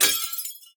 glass6.ogg